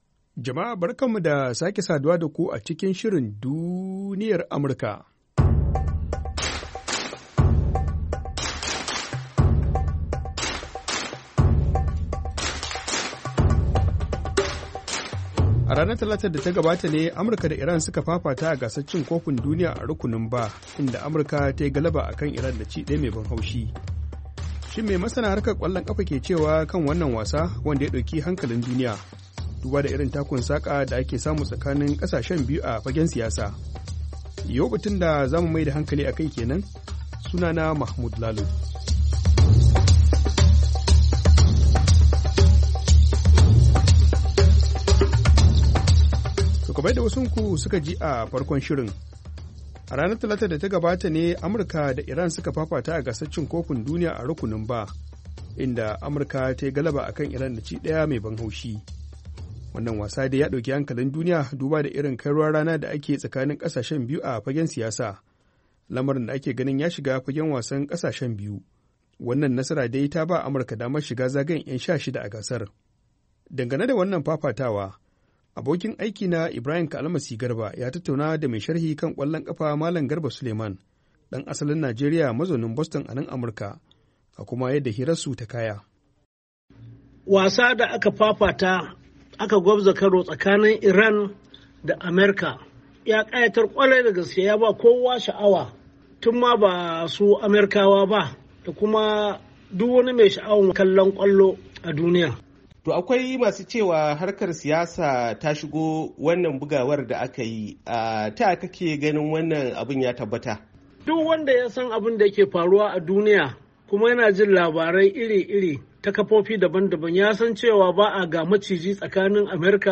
DUNIYAR AMURKA: Tattaunawa Kan Karawar Amurka Da Iran A Gasar Cin Kofin Duniya A Qatar - 6'00"